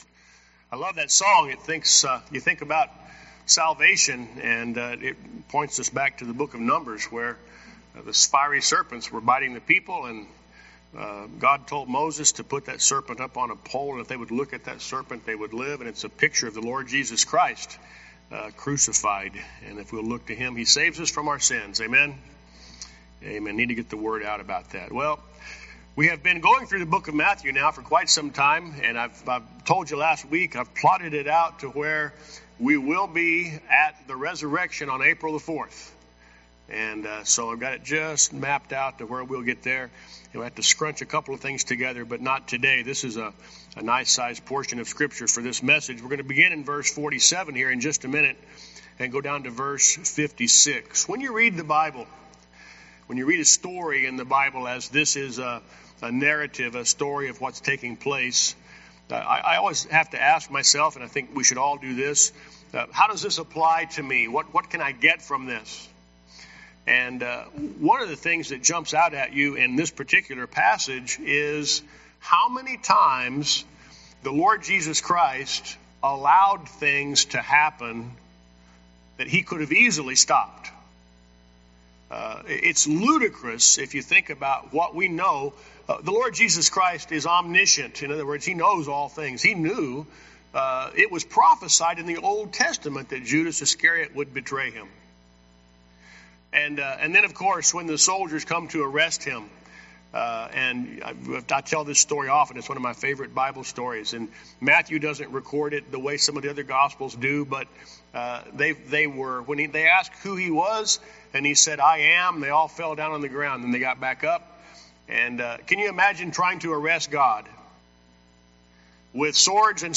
Series: Guest Speaker